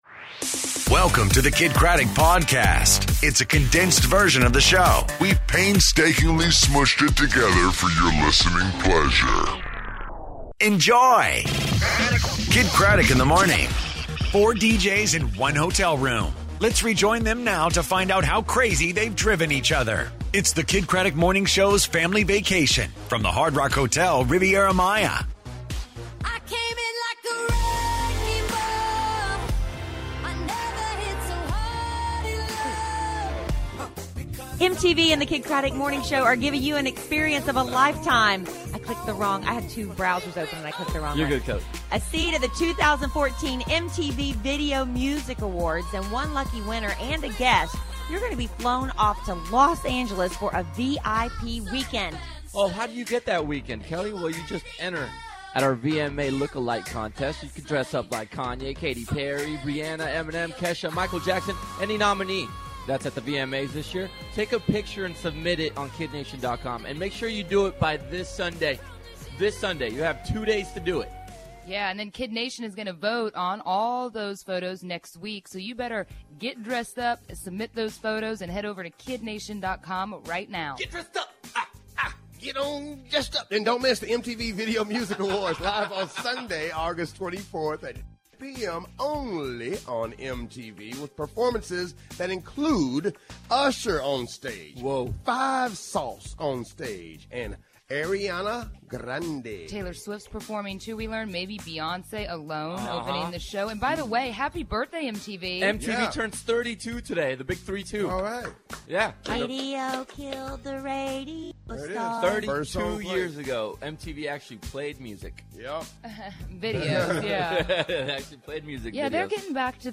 Live from Mexico!